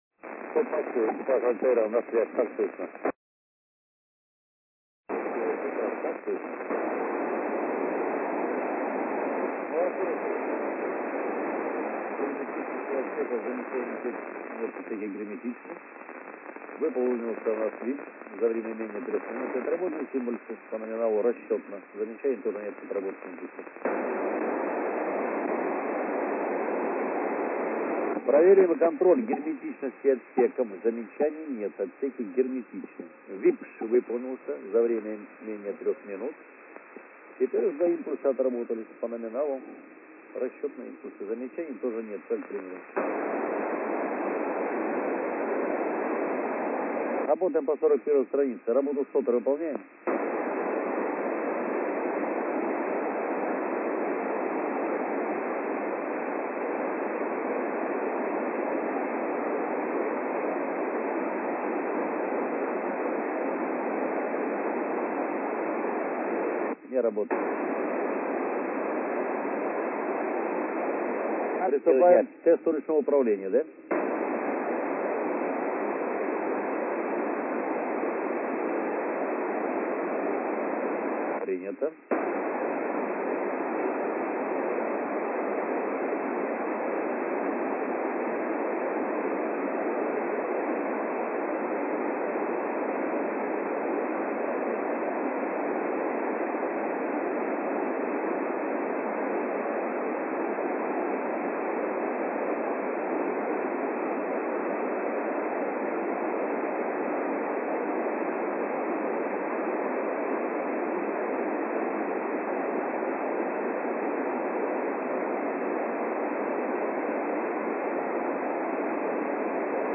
Начало » Записи » Записи радиопереговоров - МКС, спутники, наземные станции
Связь экипажа Союз ТМА-16М с ЦУПом, первый виток после разделения с ракетой-носителем.